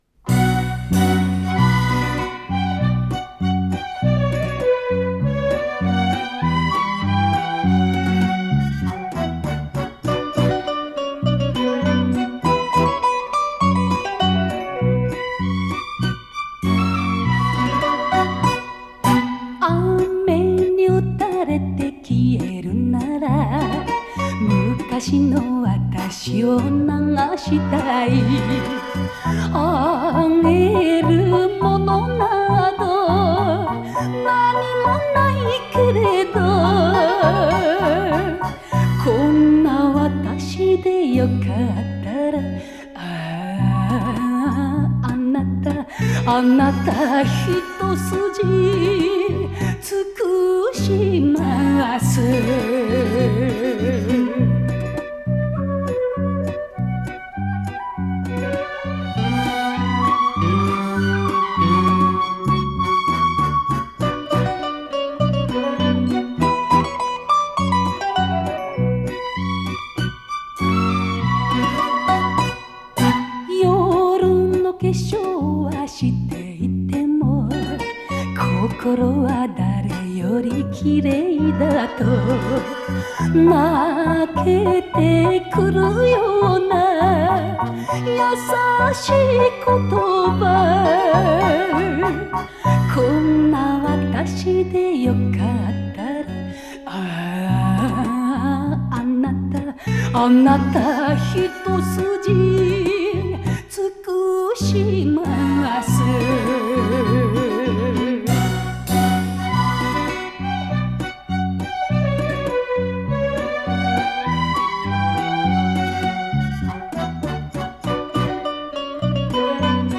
picЖанр: Enka